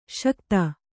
♪ śakta